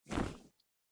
EagleWingFlap.wav